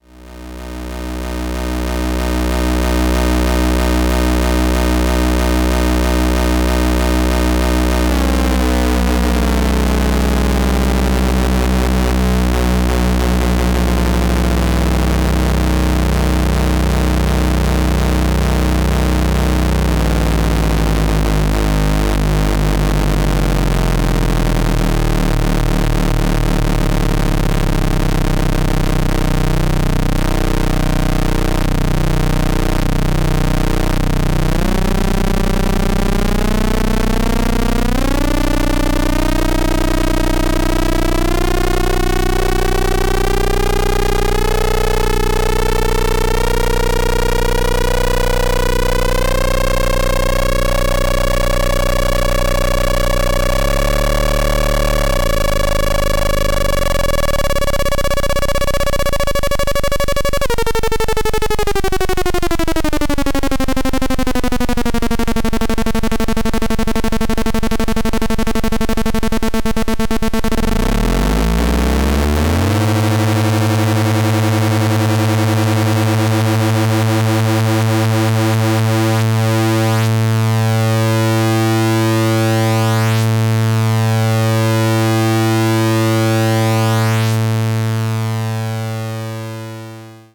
Mixing two audio signals: a saw wave and a square wave superimposed.